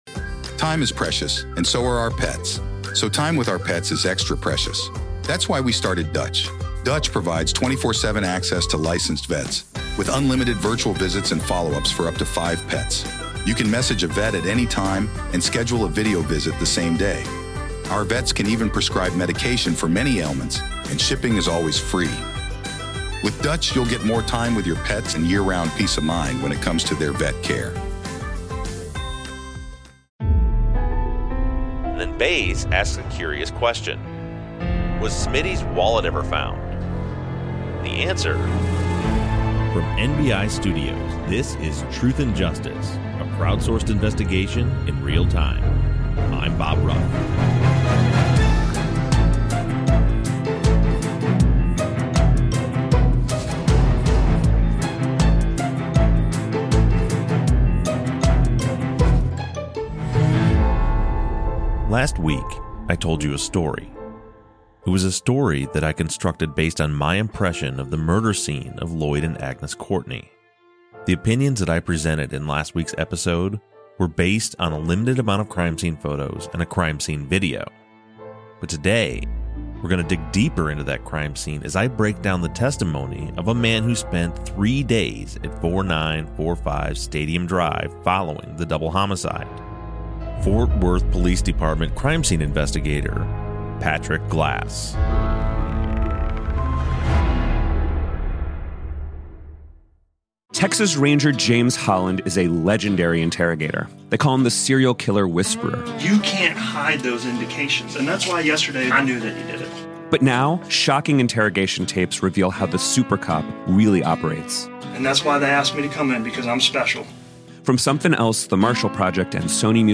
True Crime, Documentary, Society & Culture